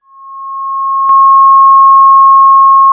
BadTransmission4.wav